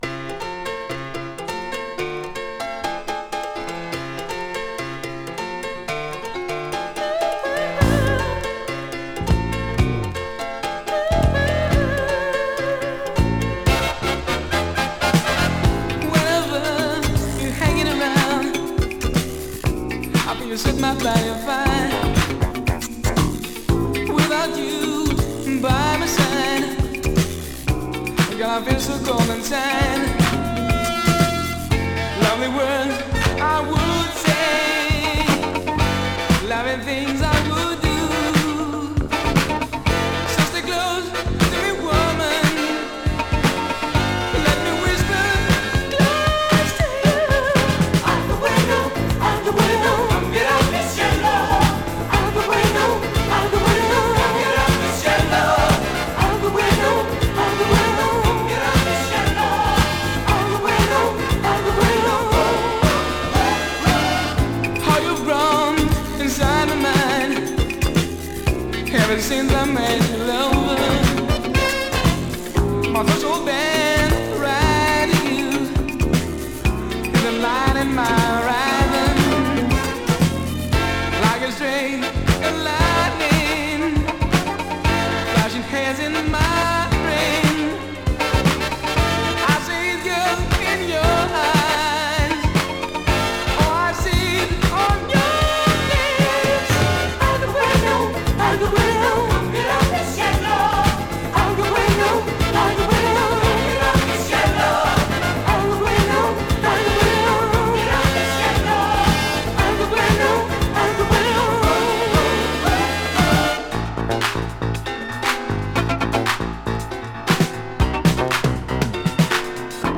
【BOOGIE】【DISCO】